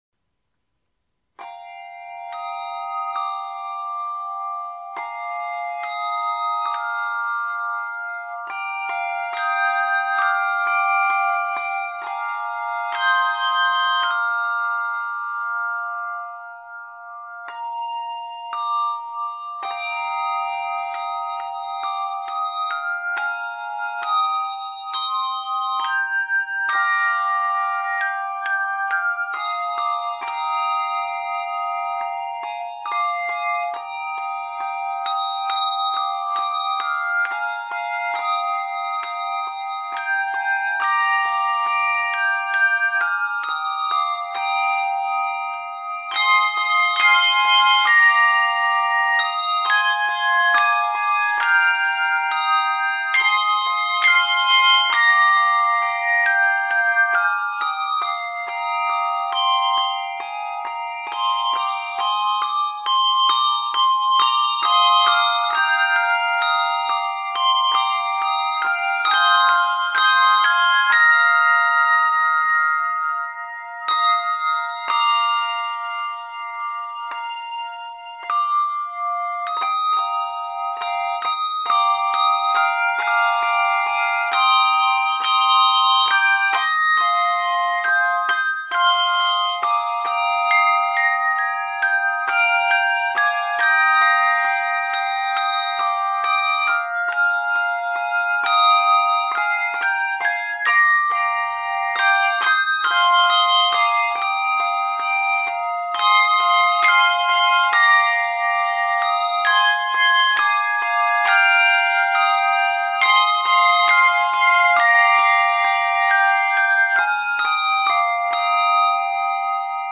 ringing